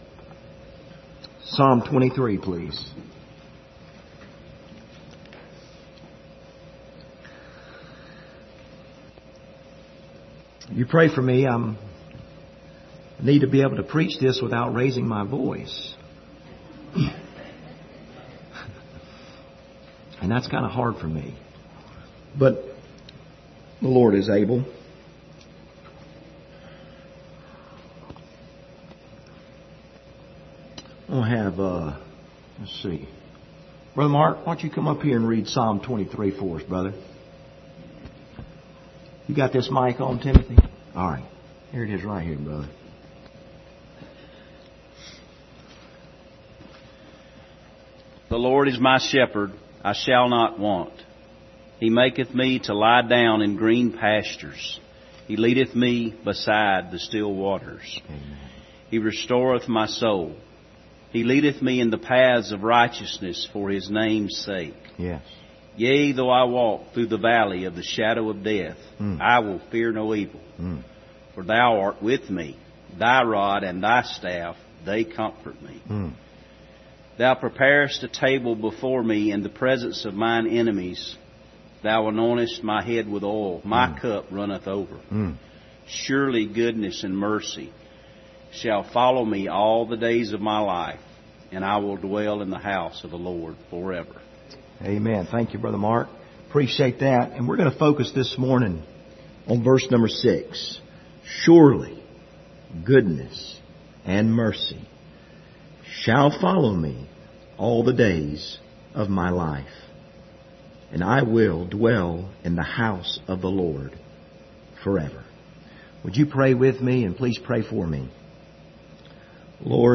Passage: Psalms 23 Service Type: Sunday Morning